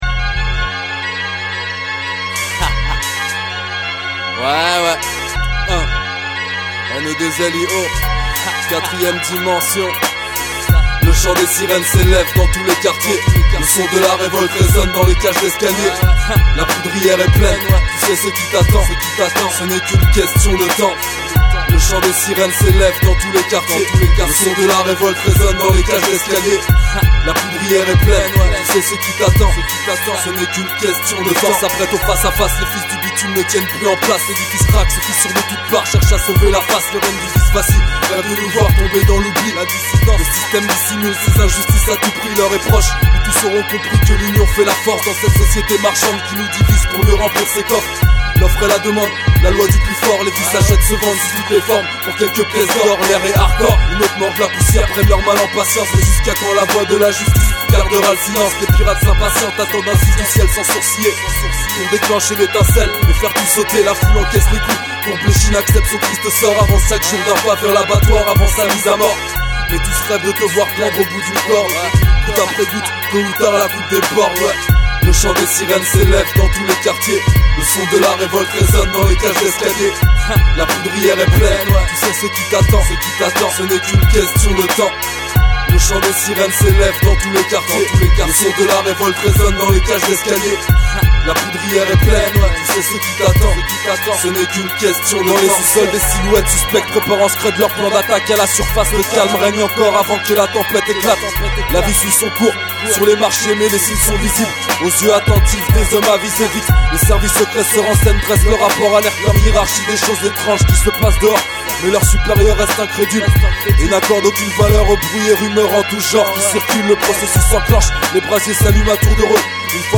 mc & instru